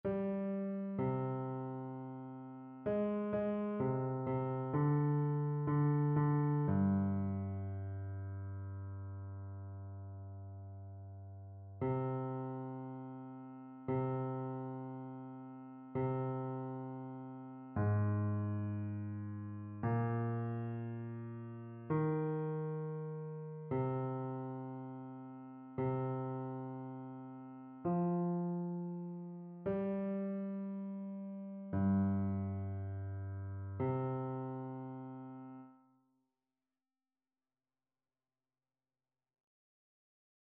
Basse
annee-b-temps-ordinaire-22e-dimanche-psaume-14-basse.mp3